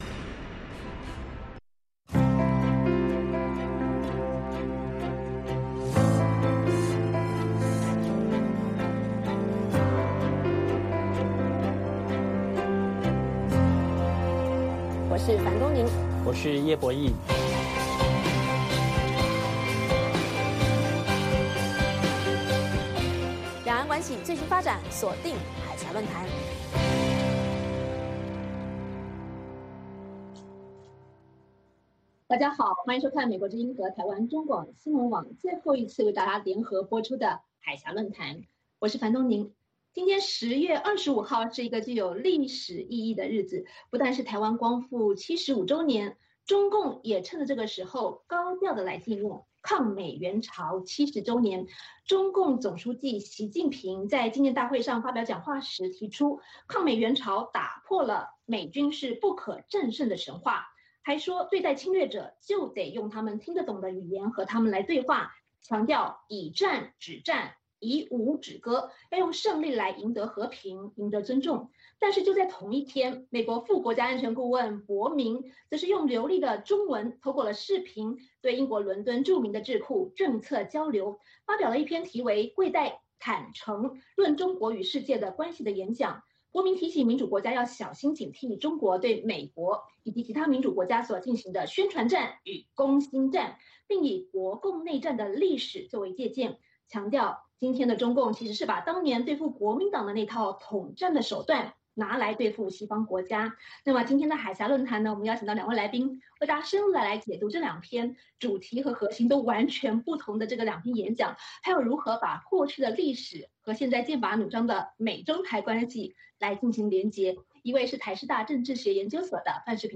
美国之音中文广播于北京时间每周日晚上9-10点播出《海峡论谈》节目(电视、广播同步播出)。《海峡论谈》节目邀请华盛顿和台北专家学者现场讨论政治、经济等各种两岸最新热门话题。